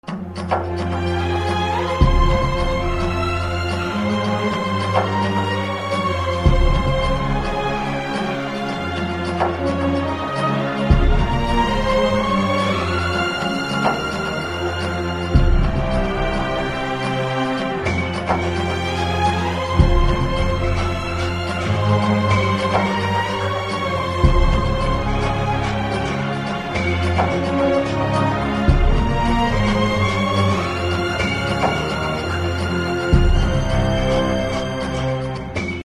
Попса [41]